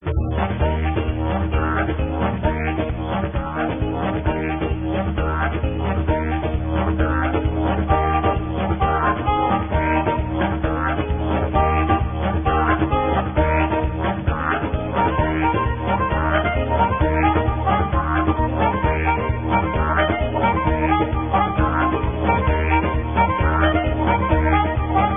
Non-traditional Didjeridu
guitar
drums